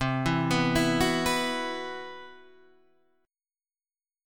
Esus2/C Chord